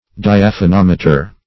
Search Result for " diaphanometer" : The Collaborative International Dictionary of English v.0.48: Diaphanometer \Di`a*pha*nom"e*ter\, n. [Gr.